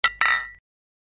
دانلود آهنگ آب 70 از افکت صوتی طبیعت و محیط
جلوه های صوتی
دانلود صدای آب 70 از ساعد نیوز با لینک مستقیم و کیفیت بالا